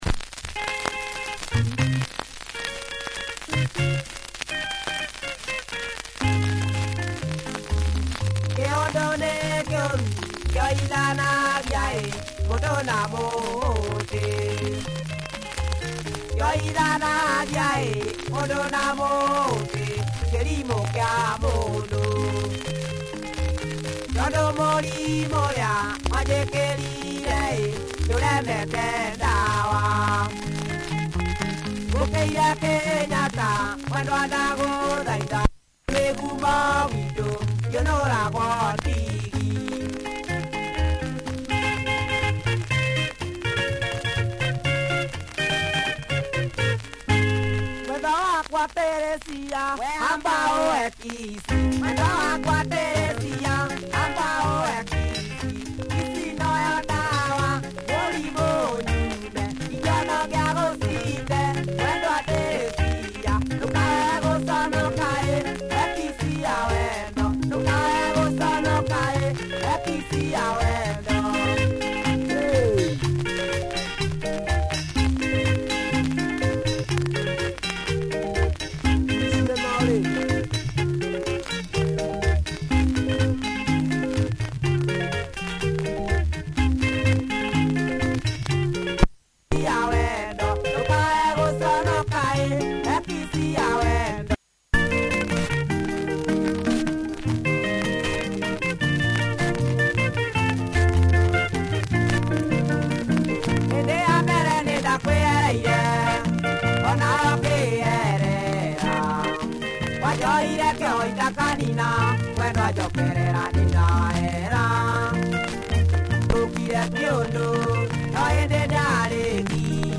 Nice kikuyu benga, disc has wear! https